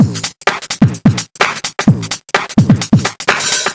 VMH1 Minimal Beats 15.wav